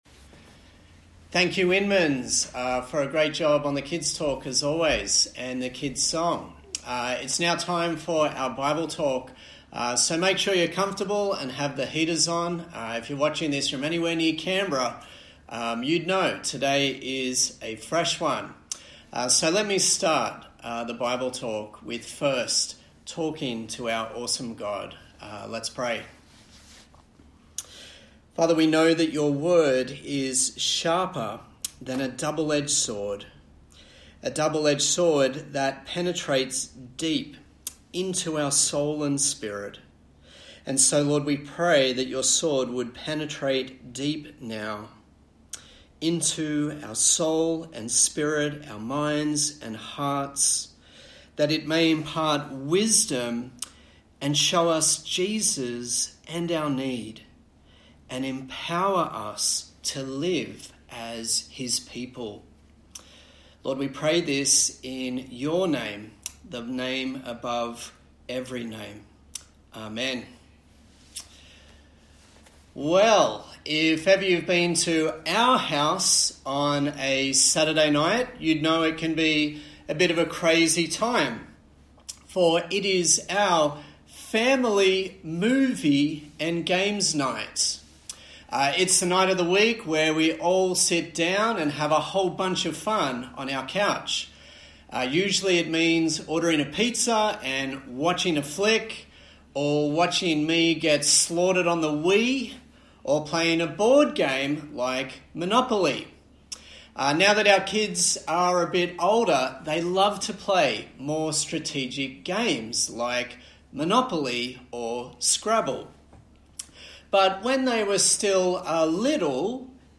Series: Ecclesiastes Passage: Ecclesiastes 10:8-18 Service Type: Sunday Morning